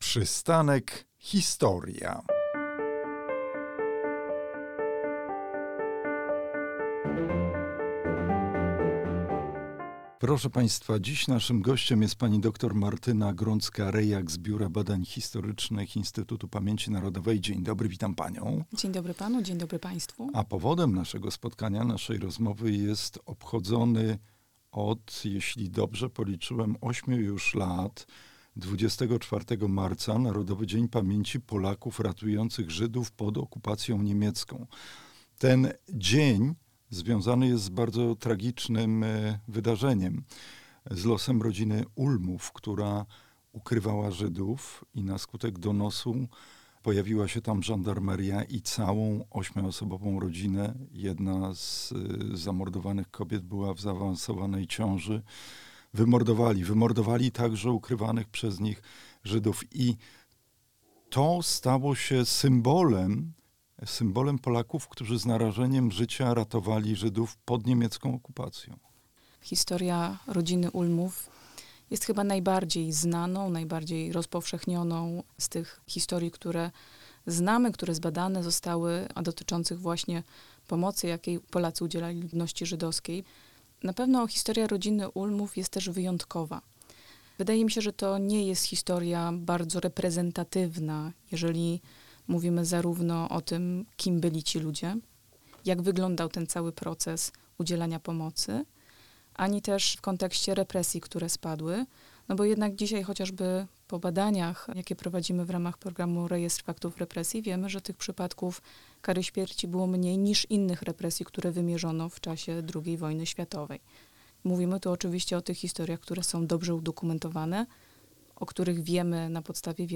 Polacy ratujących Żydów w badaniach historycznych. Rozmowa